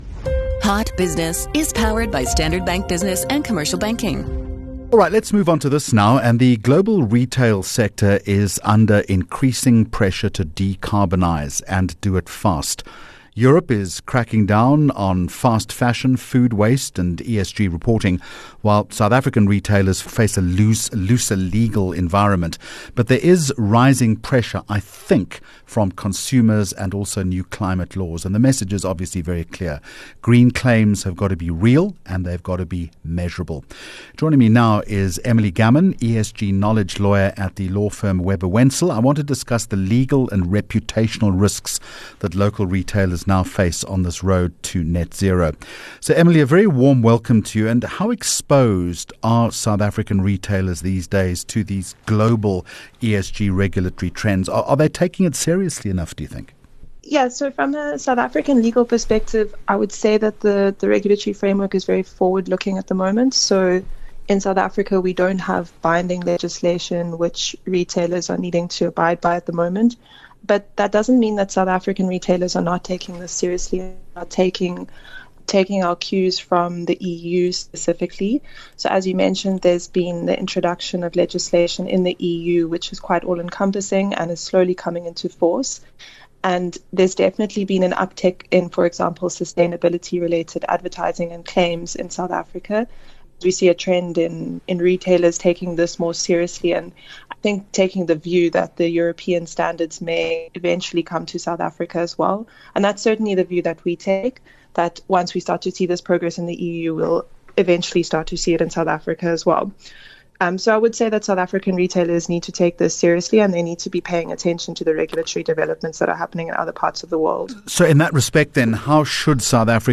30 Jun Hot Business Interview